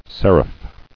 [cer·iph]